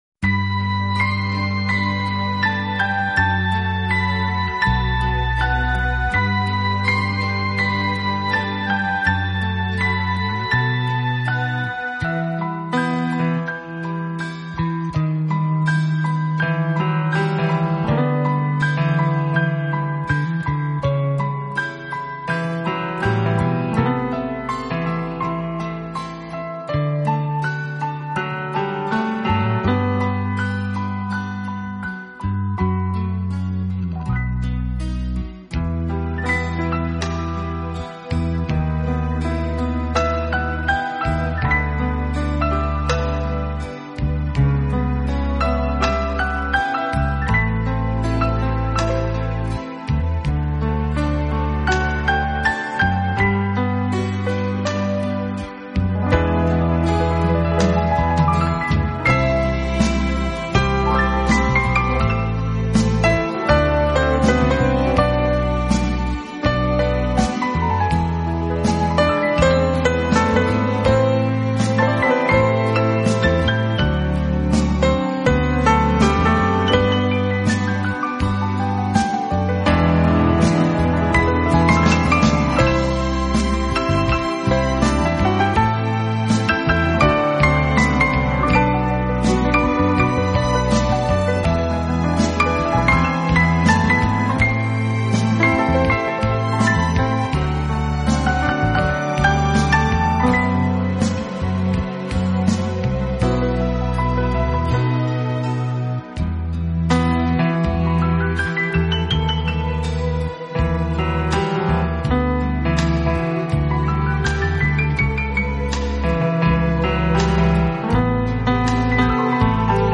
【浪漫钢琴】
钢琴演奏版，更能烘托出复古情怀，欧美钢琴大师深具质感的演奏功力，弹指
本套CD全部钢琴演奏，